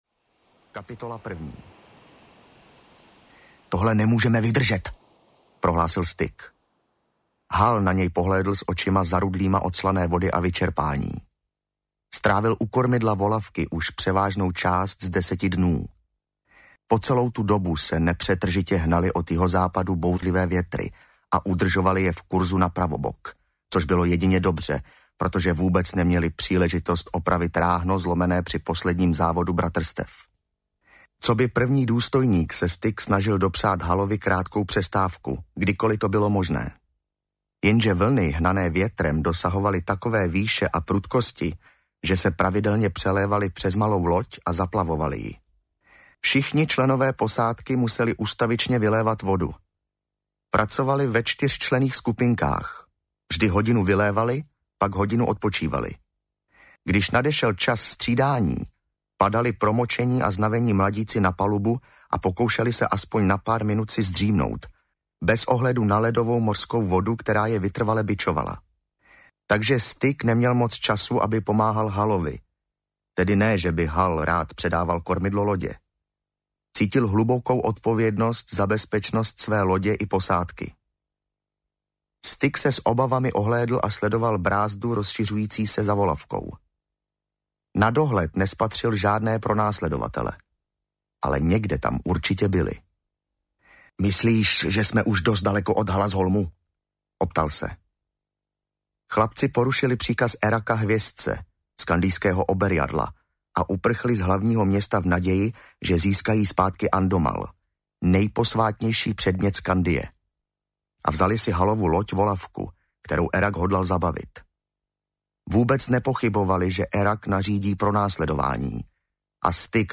Audio knihaBratrstvo Kniha druhá - Nájezdníci
Ukázka z knihy
• InterpretPavel Neškudla